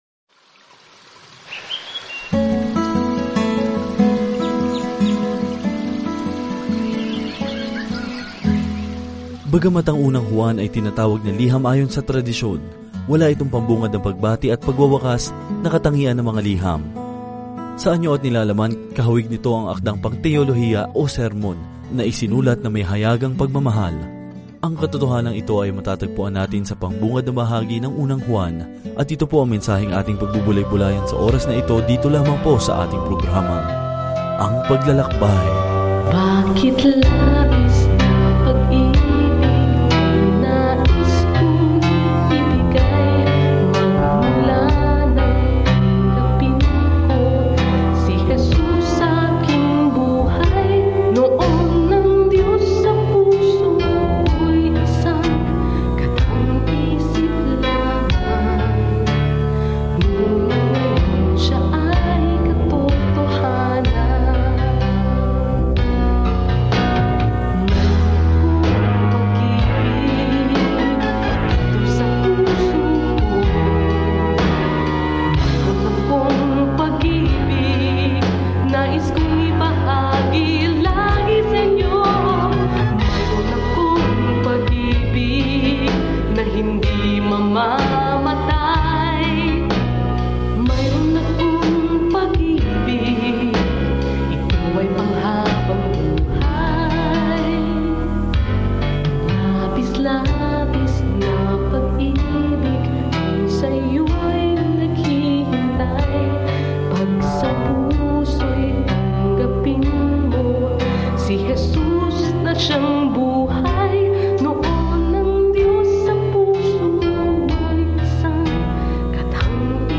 Banal na Kasulatan 1 Juan 1:1 Umpisahan ang Gabay na Ito Araw 2 Tungkol sa Gabay na ito Walang gitnang lupa sa unang liham na ito mula kay Juan - piliin man natin ang liwanag o dilim, katotohanan sa kasinungalingan, pag-ibig o poot; niyakap natin ang isa o ang isa, tulad ng ating paniniwala o pagtanggi sa Panginoong Jesucristo. Araw-araw na paglalakbay sa 1 John habang nakikinig ka sa audio study at nagbabasa ng mga piling talata mula sa salita ng Diyos.